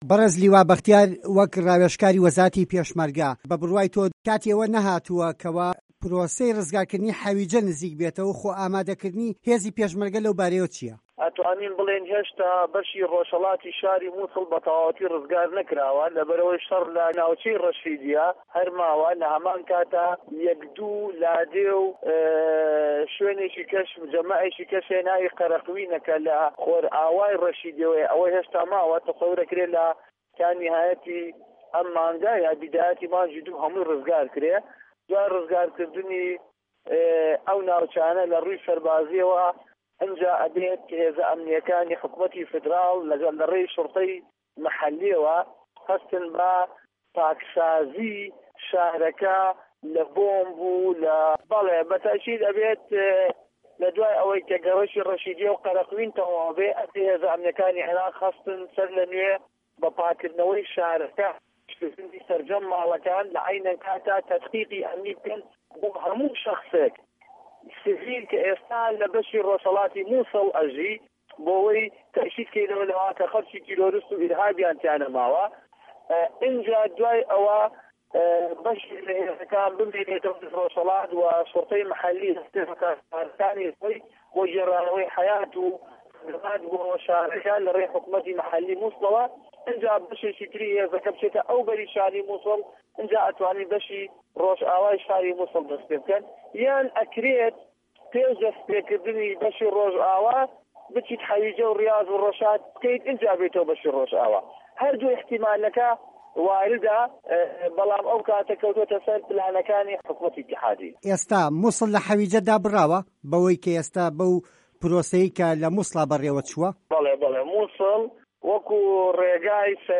وتووێژ لەگەڵ لیوا بەختیار محەمەد